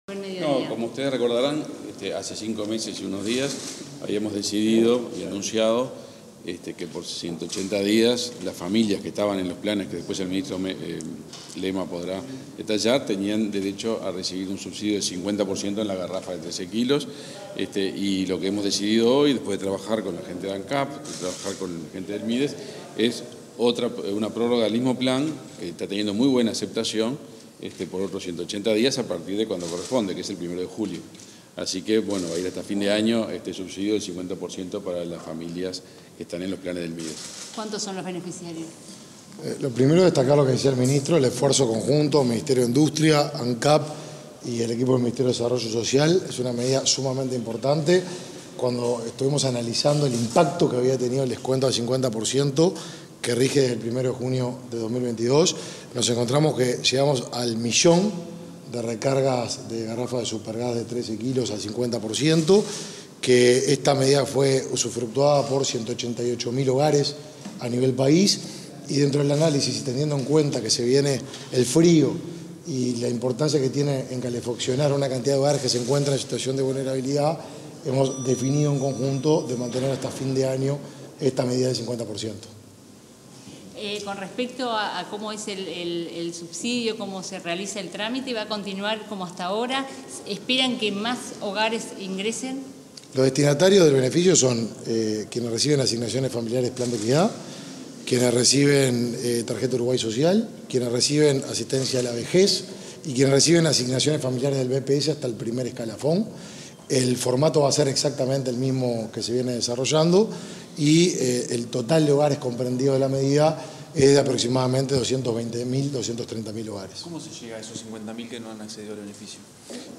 Declaraciones de los ministros de Energía y Desarrollo Social